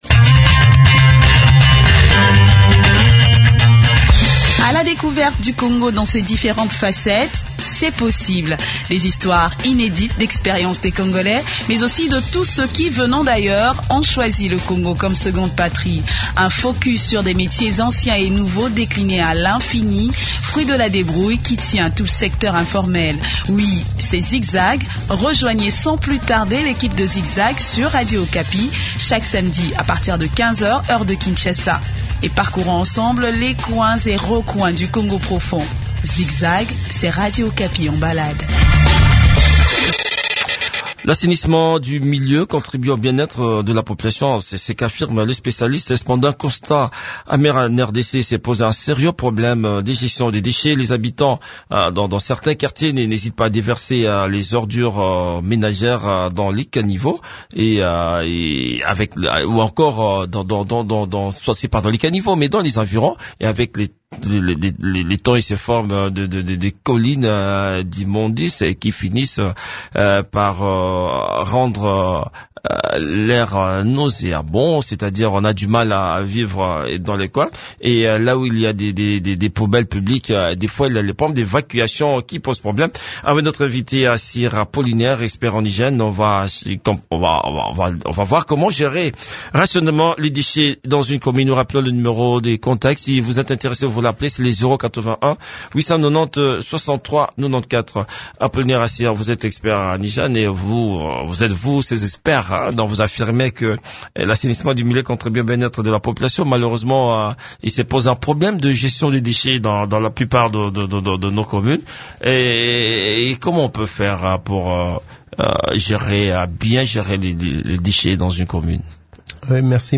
expert en hygiène.